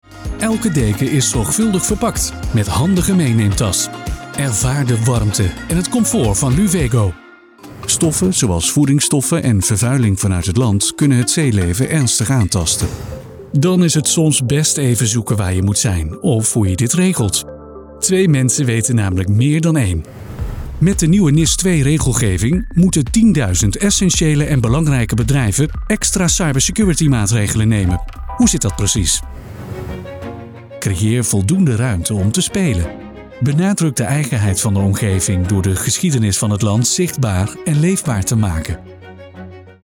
Reliable, Friendly, Corporate
Corporate